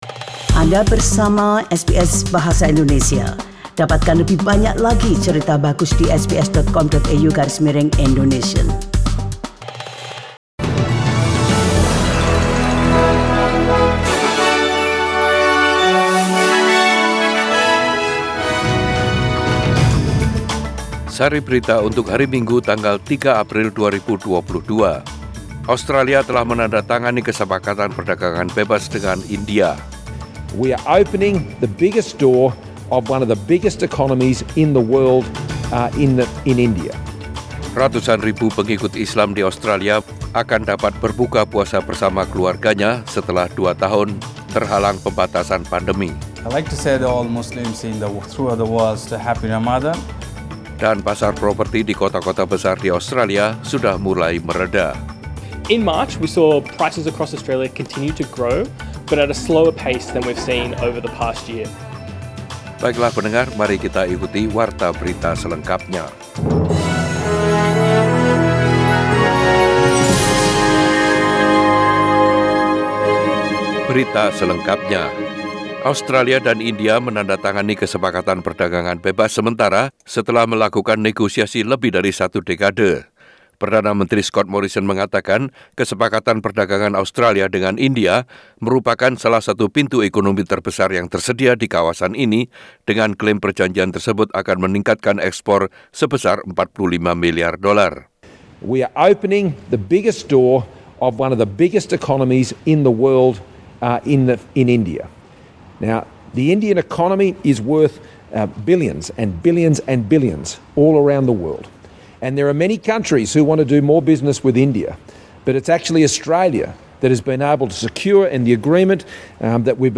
Warta Berita Radio SBS Program Bahasa Indonesia.